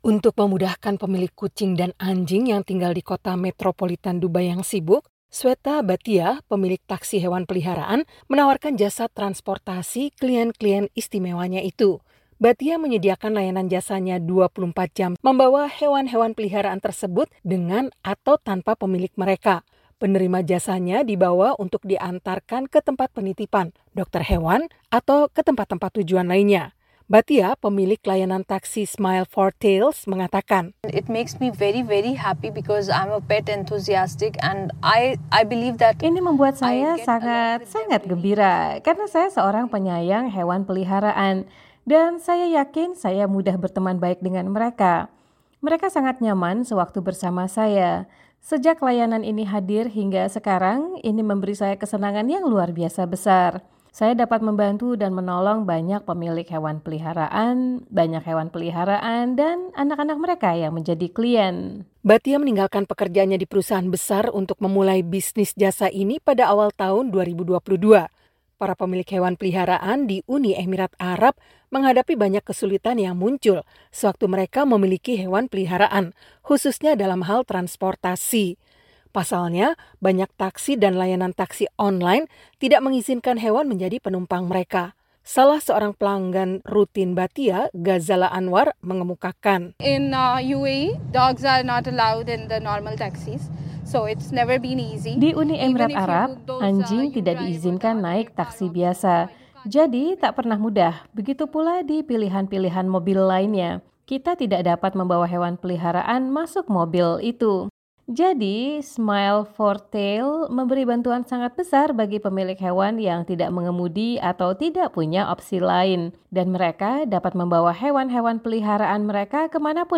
Smile for Tails, sebuah layanan taksi berbasis di Dubai menawarkan jasa 24 jam sehari mengantar hewan-hewan peliharaan ke pemilik mereka yang tinggal di tengah kota metropolitan yang sibuk itu. Berikut laporan tim VOA.